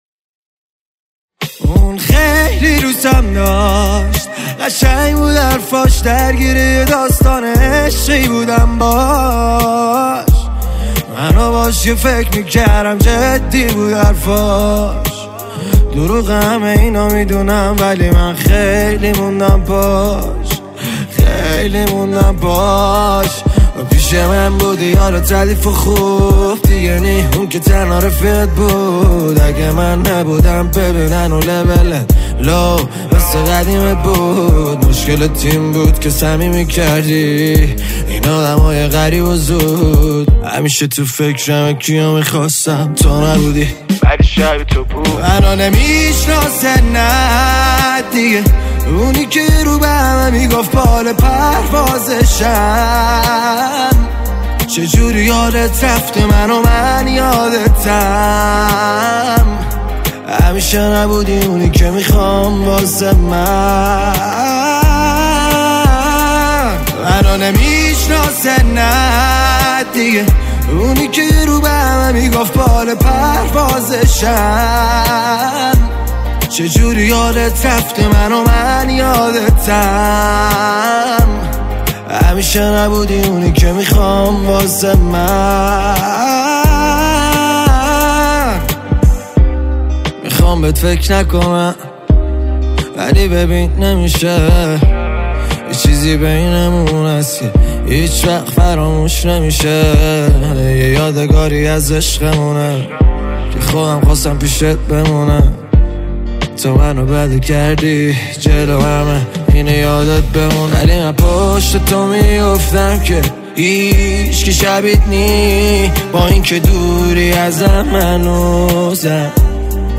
موسیقی پاپ